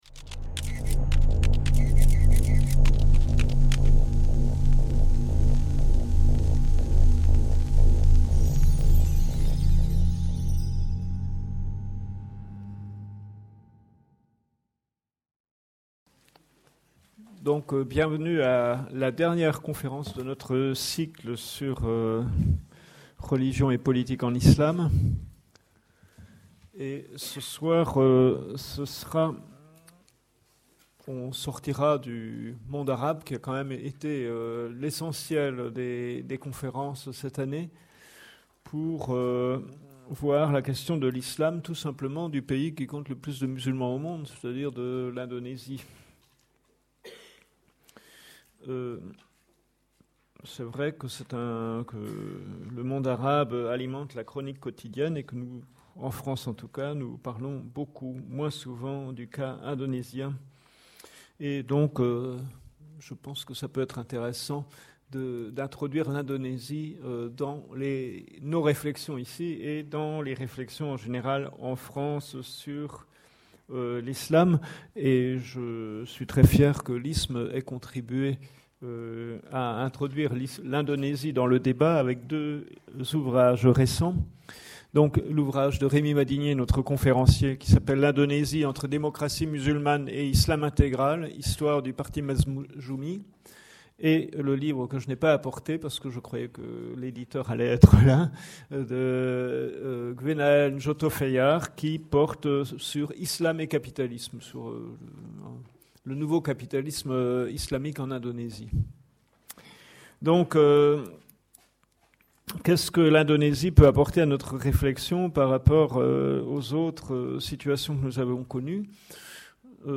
15 - Conférence